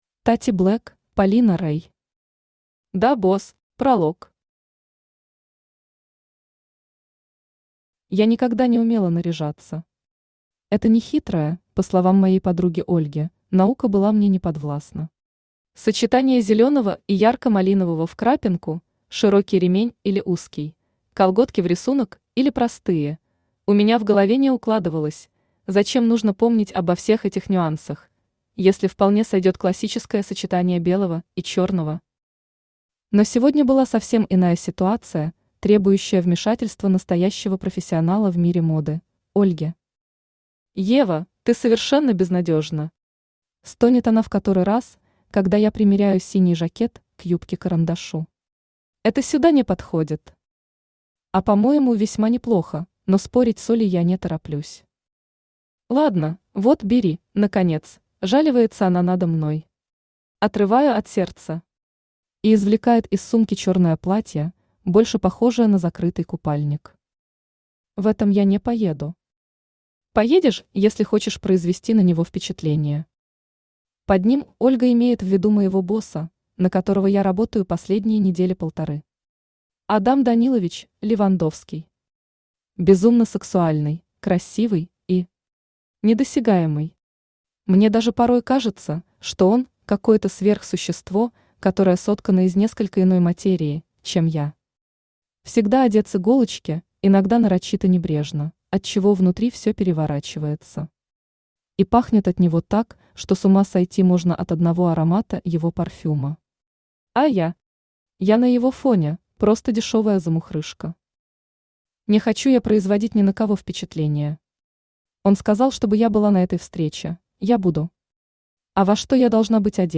Аудиокнига Да, Босс!
Автор Тати Блэк Читает аудиокнигу Авточтец ЛитРес.